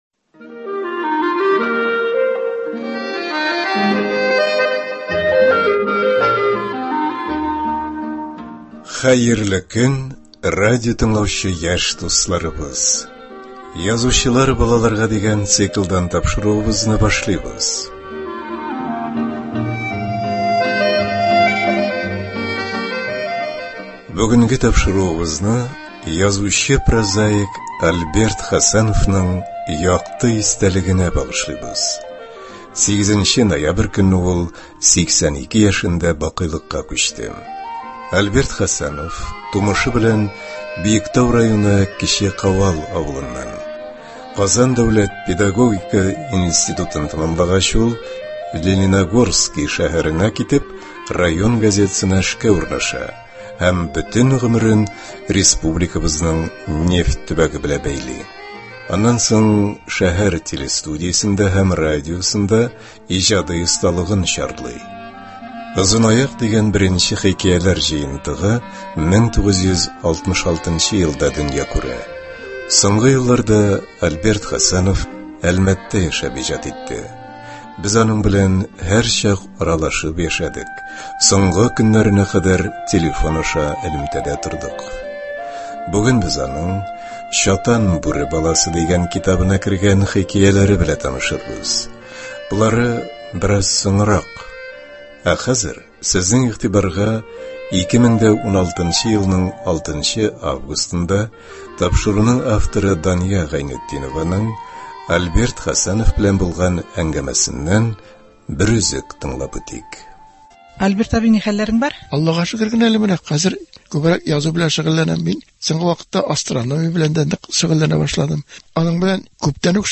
әңгәмәдән өзек